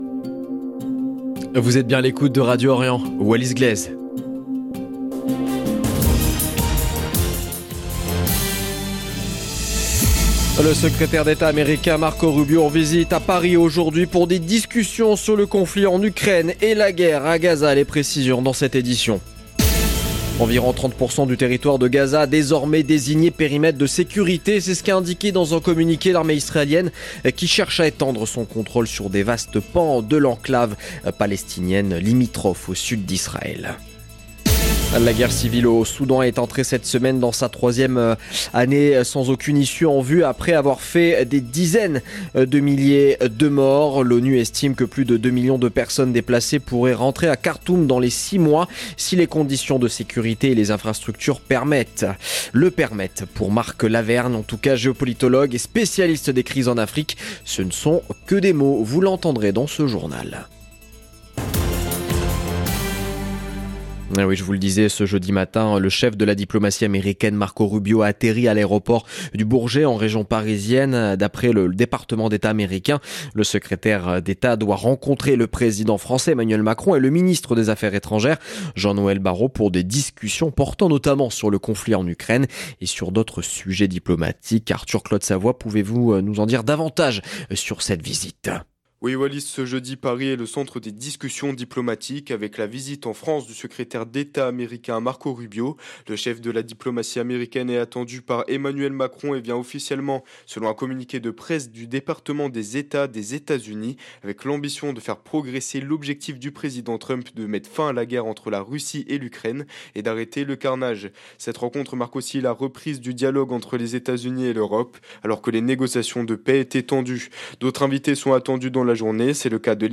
LE JOURNAL EN LANGUE FRANÇAISE DE MIDI 17/04/2025